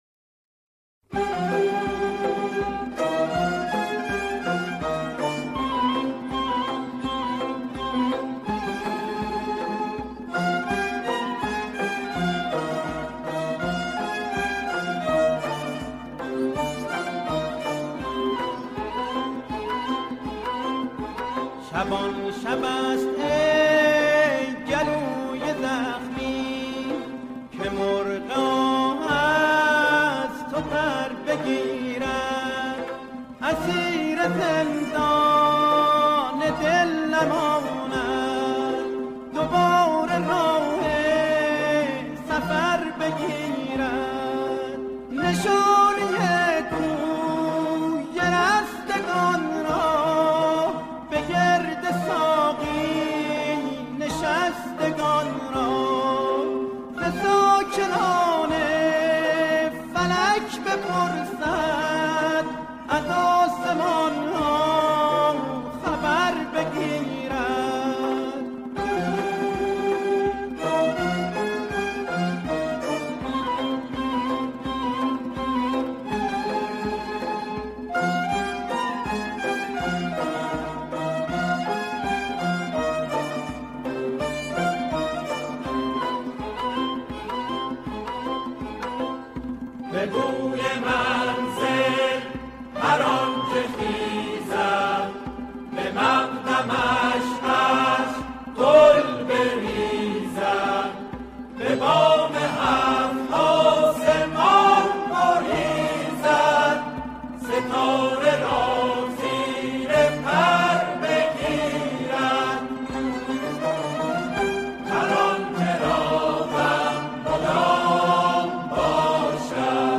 در این قطعه، شعری با موضوعی عید عرفانی همخوانی می‌شود.